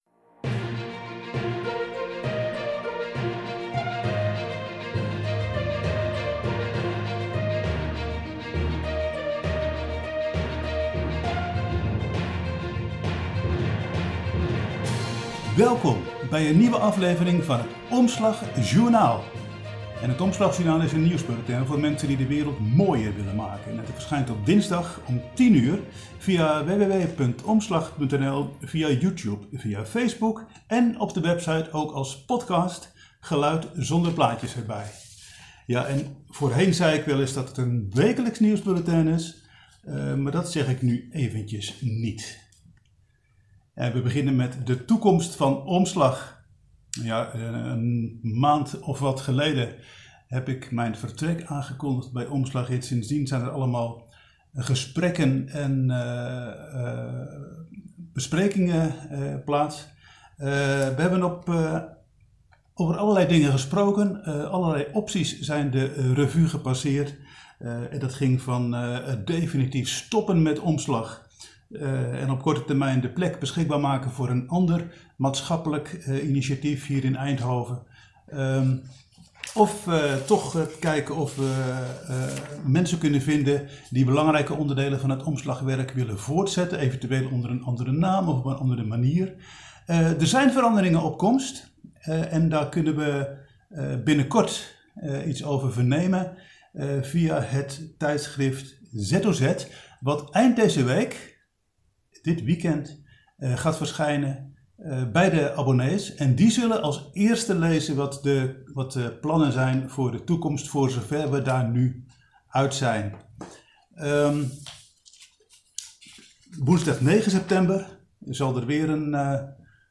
EINDHOVEN, 25 augustus 2020 - De internationale campagne tegen Ecocide, het nieuwe afwassen en de toekomst van Omslag zijn enkele belangrijke onderwerpen in de dertiende aflevering van het Omslag Journaal. Het Omslag Journaal is een nieuwsrubriek voor mensen die de wereld mooier willen maken.